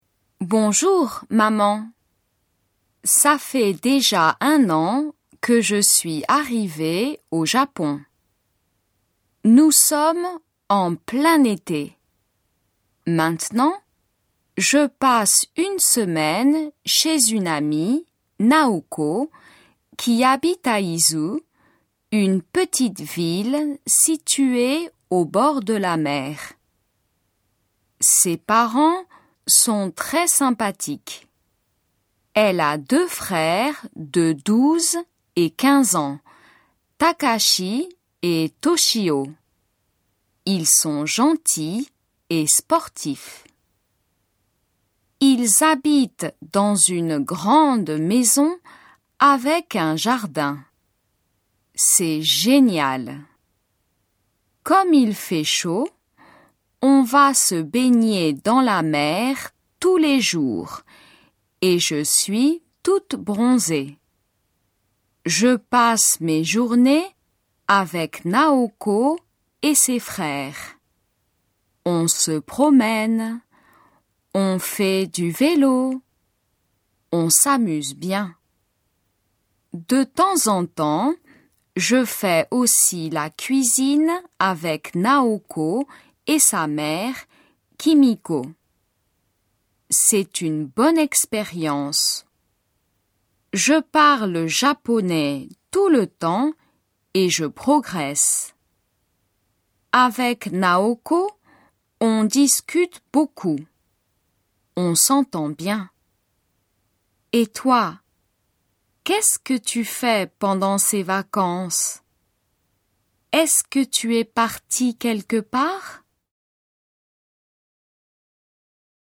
仏検準２級の聞き取り問題です。